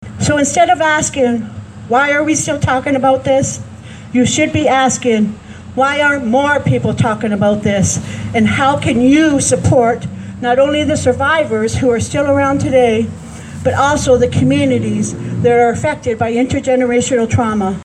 The one-hour event was a commemoration of Orange Shirt Day and National Day for Truth and Reconciliation.